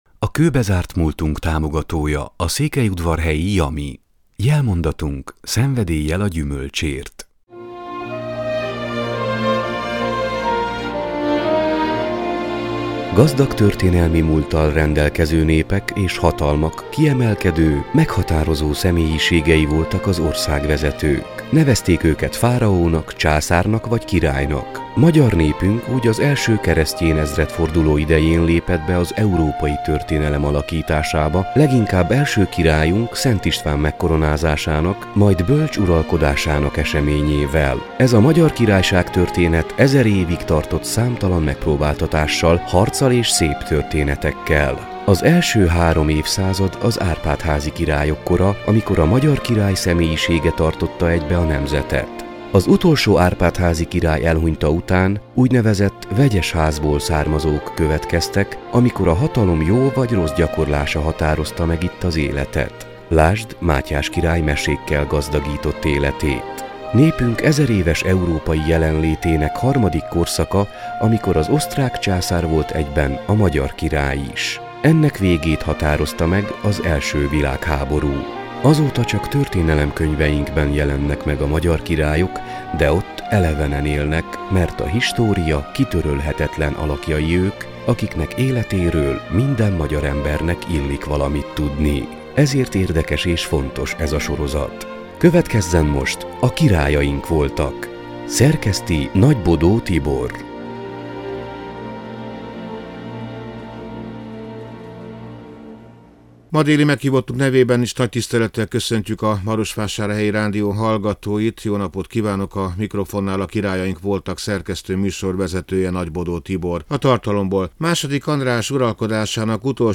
beszélget